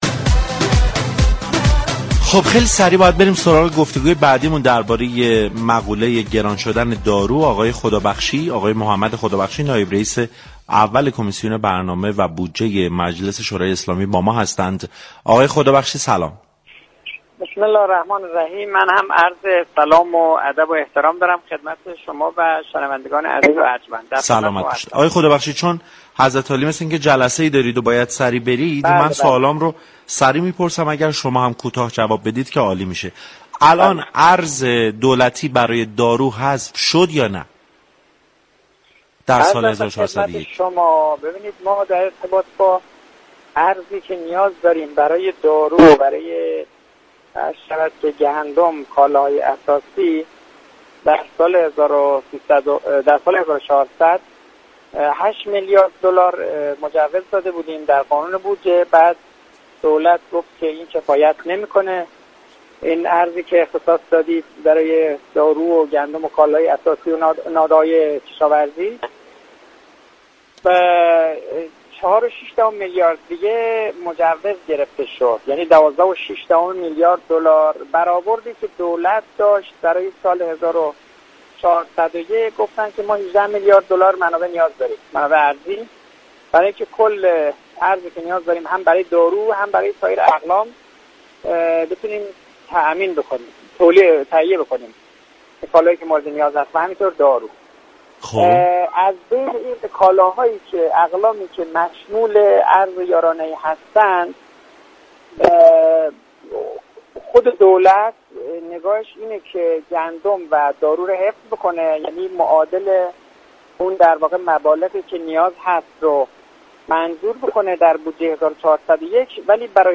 در همین راستا محمد خدابخشی نایب رئیس اول كمیسیون برنامه و بودجه مجلس یازدهم در گفتگو با برنامه سعادت آباد رادیو تهران در خصوص حذف ارز دولتی دارو گفت: در سال جاری 8 میلیارد دلار ارز برای تامین دارو، گندم و كالاهای اساسی در بودجه نظر گرفته شده بود كه با اظهار نظر دولت مبنی بر كافی نبودن این ارز 4 میلیارد و 600 میلیون دلار دیگر بر این مبلغ افزوده شد و بودجه آن به 12 میلیارد و 600 میلیون دلار رسید.